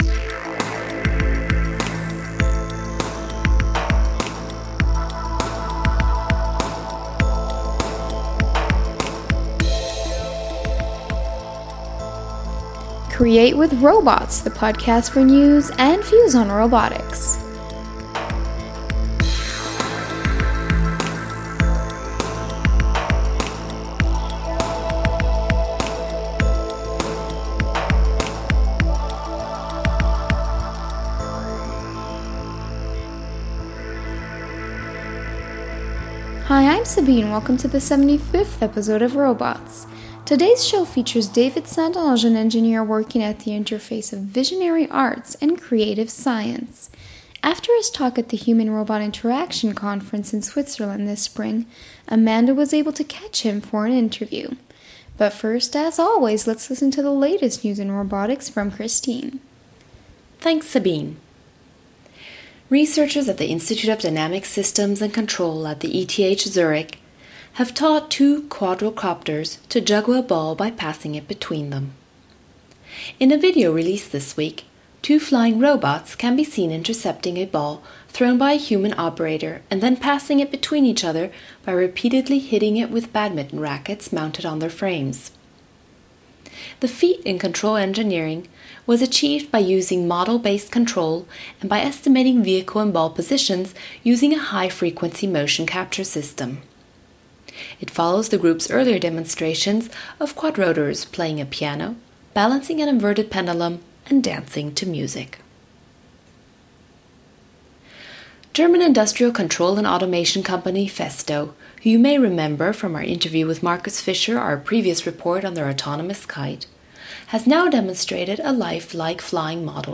Links: Download mp3 (19.1 MB) Subscribe to Robots using iTunes Subscribe to Robots using RSS tags: podcast Podcast team The ROBOTS Podcast brings you the latest news and views in robotics through its bi-weekly interviews with leaders in the field.